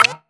rank-up.wav